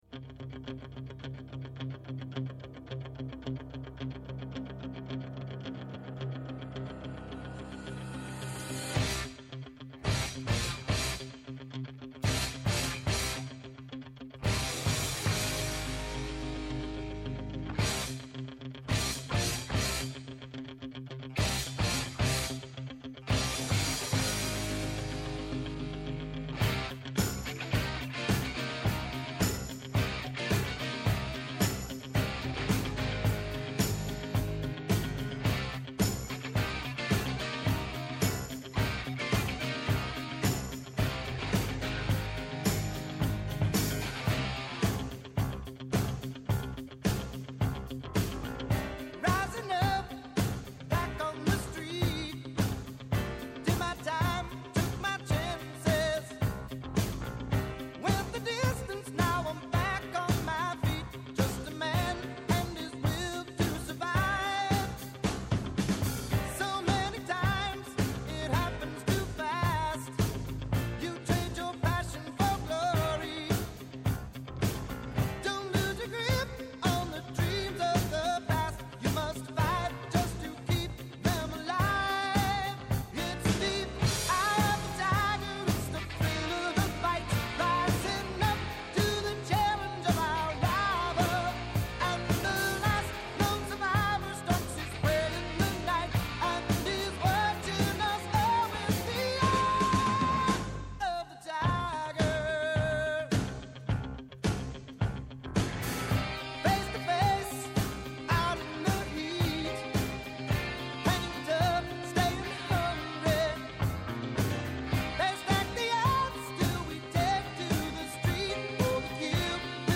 Σήμερα καλεσμένος ο Άδωνις Γεωργιάδης, Υπουργός Εργασίας.